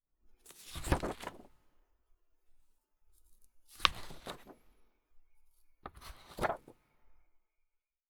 paper-sketchbook-page-flips-2.wav